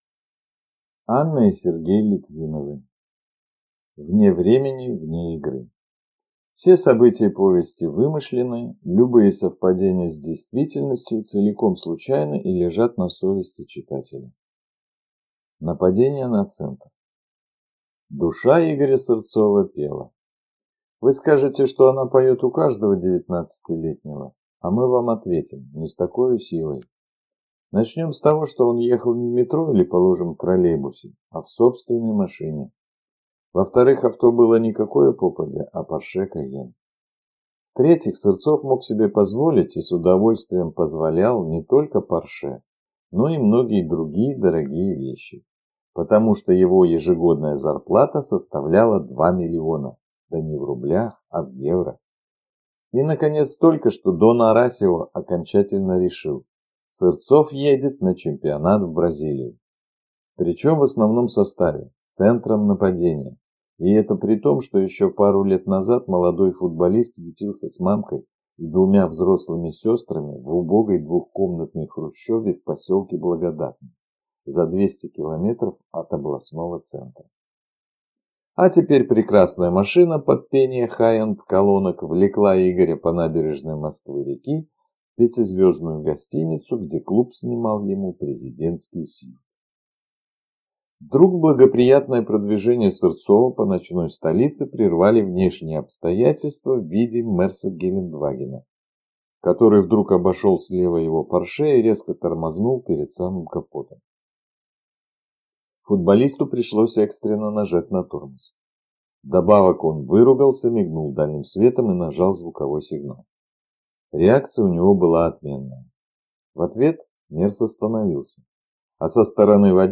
Аудиокнига Вне времени, вне игры | Библиотека аудиокниг